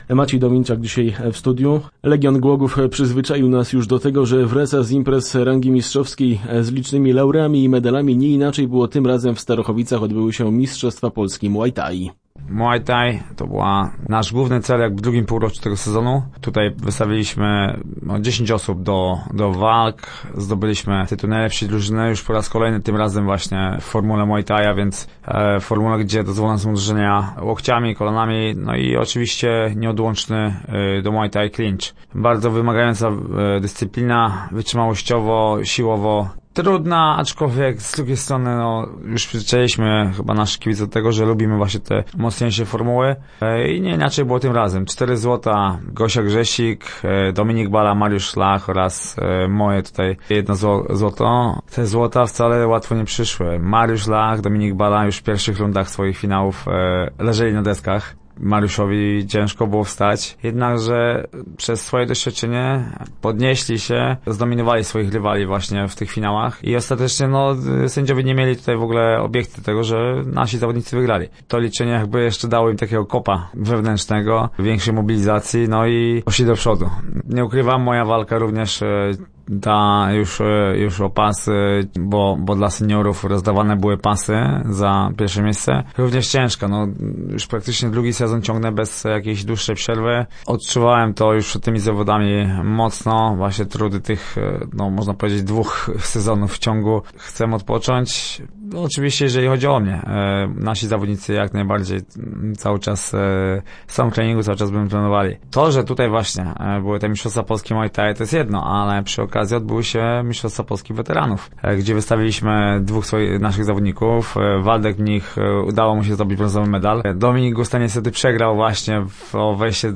Start arrow Rozmowy Elki arrow Legion najlepszy w muay-thai